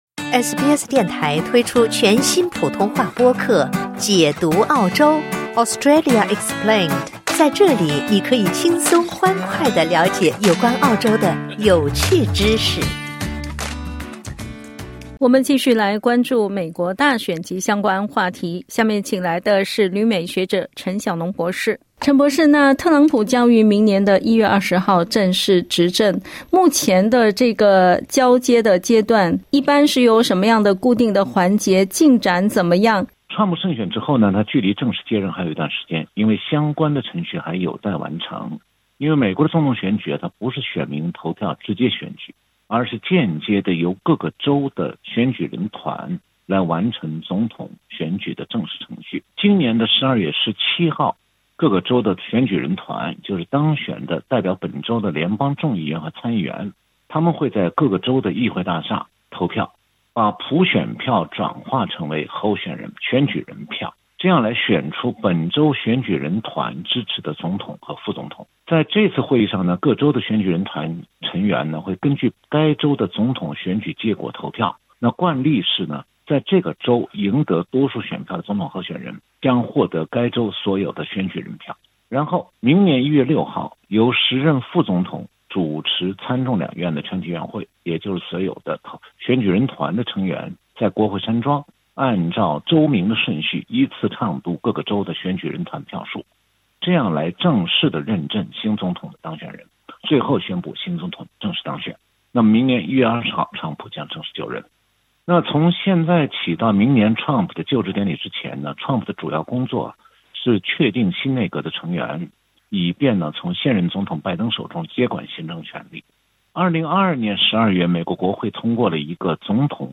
（点击音频收听详细采访） 特朗普将于明年一月二十日正式执政。